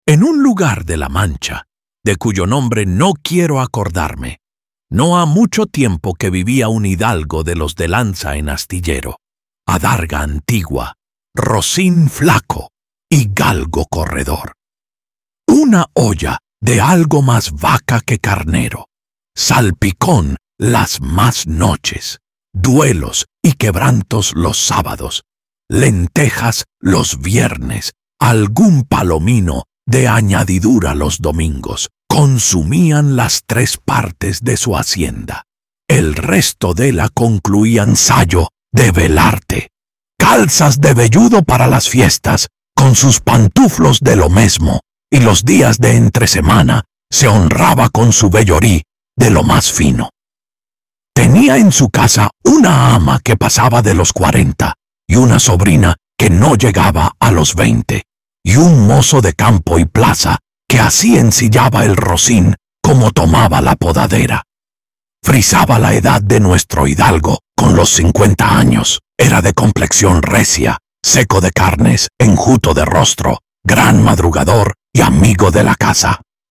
Algunos ejemplos de creación de voz con IA
Creación de audiolibros